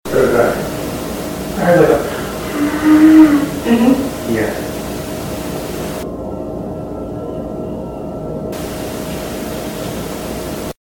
In this clip, you'll hear what the boo team thinks is a woman calling out, save me. Pay attention when the hissing sound of the audio drops.